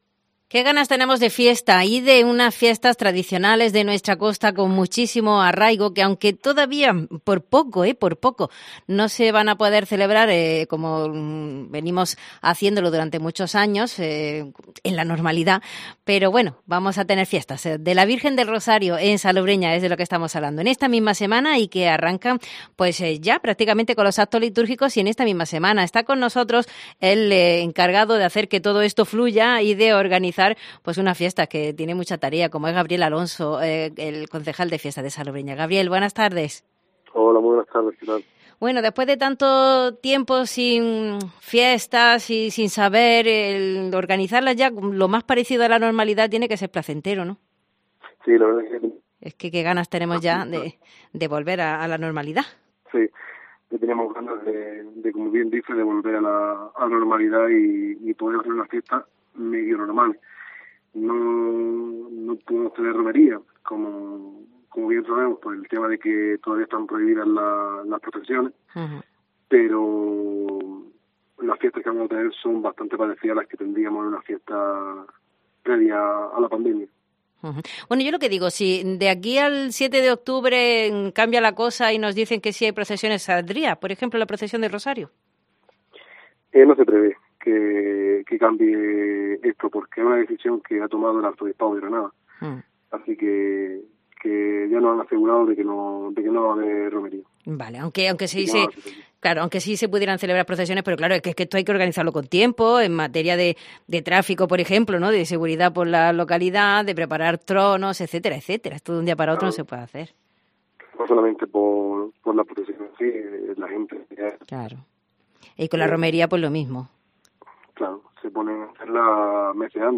El concejal de fiestas, Gabriel Alonso, nos detalla los contenidos programados del 6 al 10 de octubre